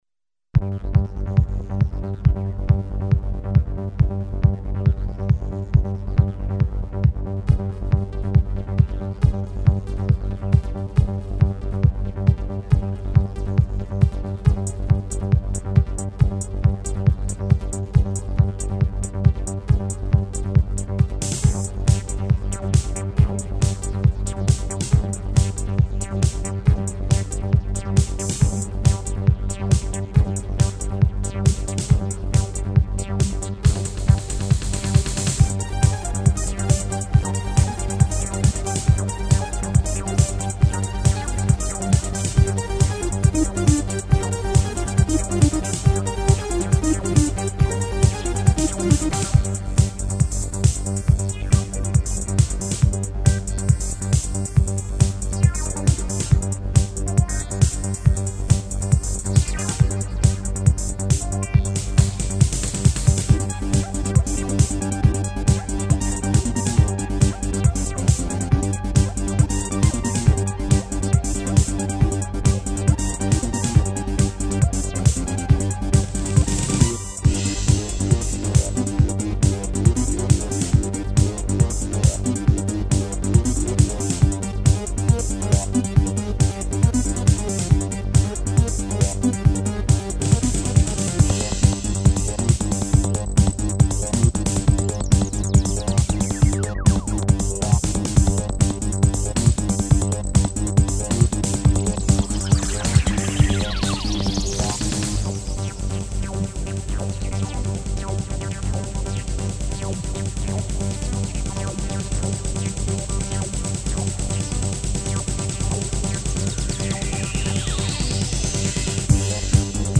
Class: Synthesizer
Synthesis: PCM rompler